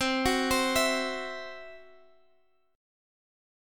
CMb5 Chord